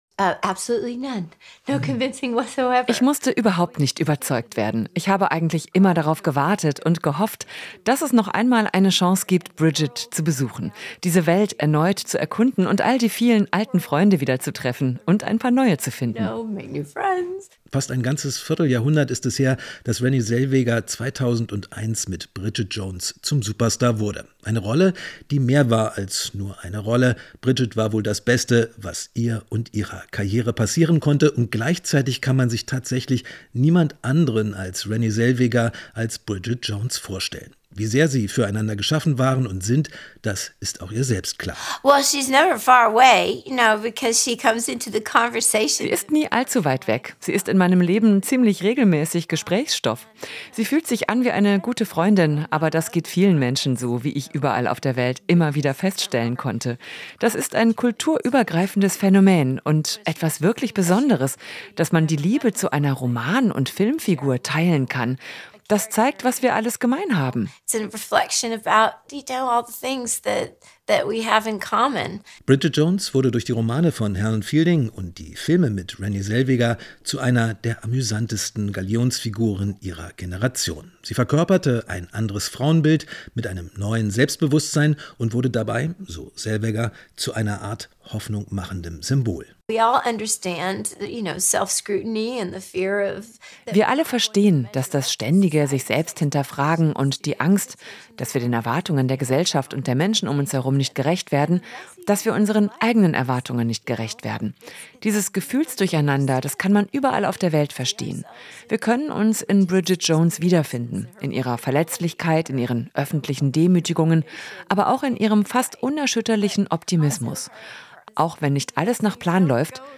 Kino-Gespräch: Renée Zellweger kehrt als Bridget Jones zurück